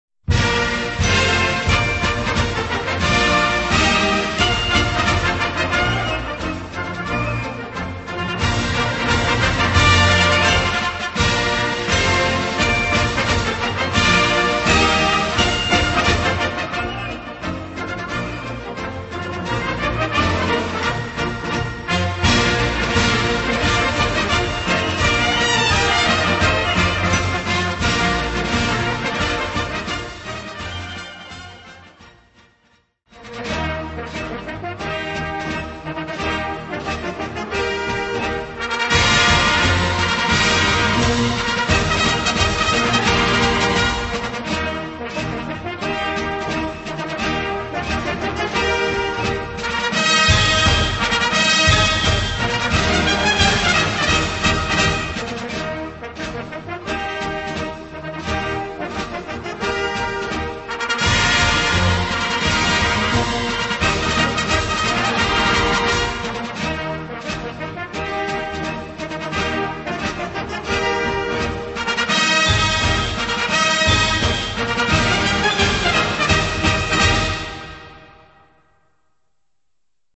Gattung: Konzertmarsch
2:40 Minuten Besetzung: Blasorchester PDF